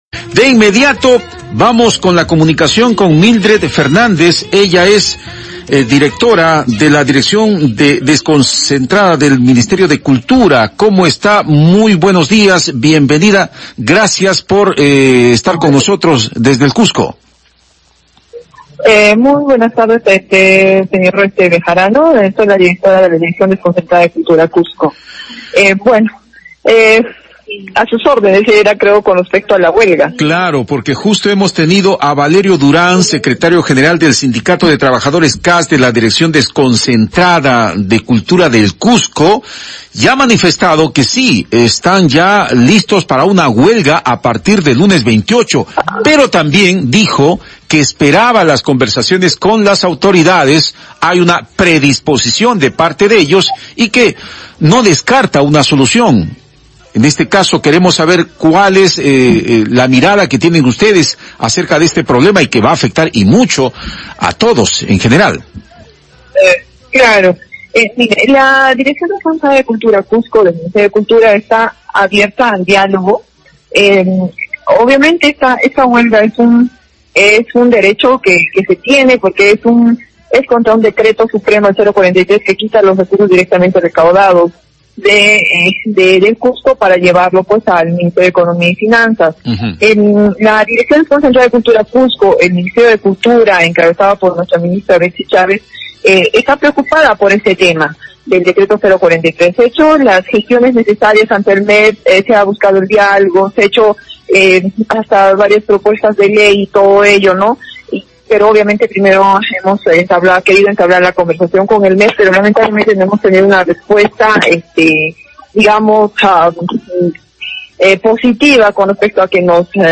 Enlace con Mildret Fernández, representante del Ministerio de Cultura